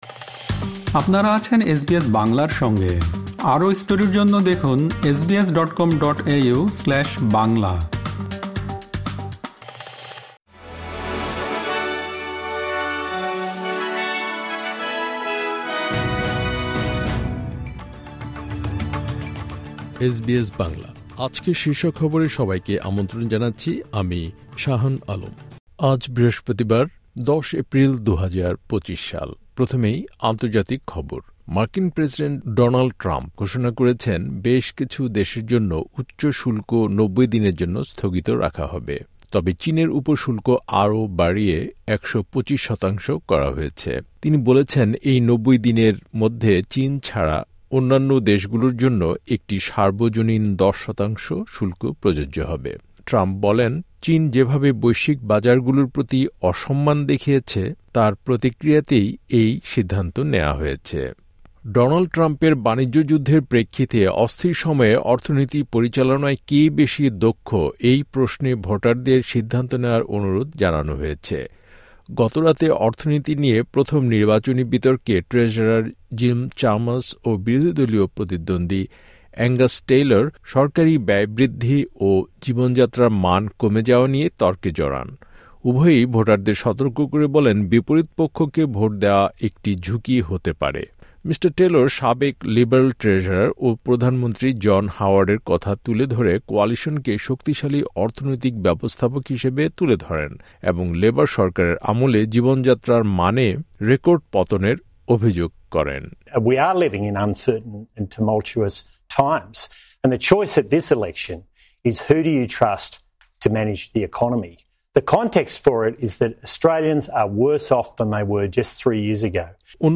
এসবিএস বাংলা শীর্ষ খবর: ১০ এপ্রিল, ২০২৫